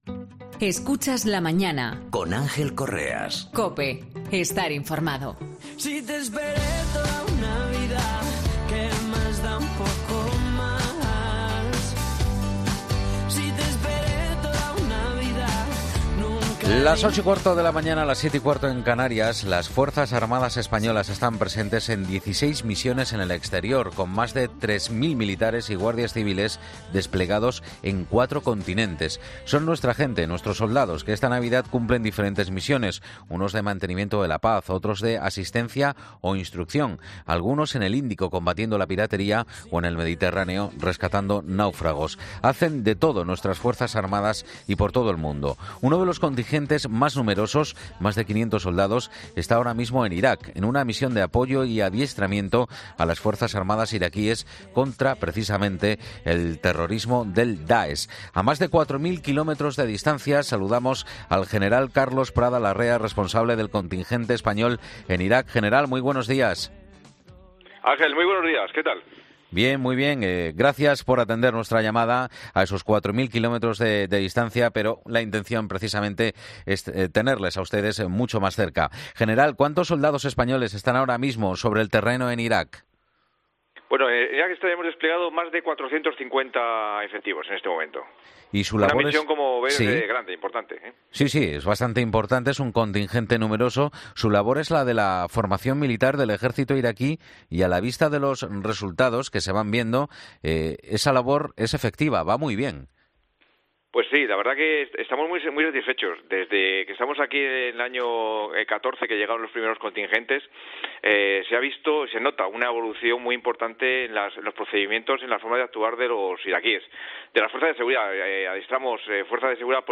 Al frente de esas tropas, del contingente español está el General Carlos Prada Larrea que ha pasado por 'La Mañana de Fin de Semana' donde ha mostrado su satisfacción por la evolución de las Fuerzas de Seguridad iraquíes, "desde que estamos aquí desde 2014 que llegaron los primeros contingentes, se ha notado una evolución en las Fuerzas de Seguridad iraquíes y se mide en lo que mejor se puede medir  en sus victorias sobre el DAESH,  la recuperación del Califato.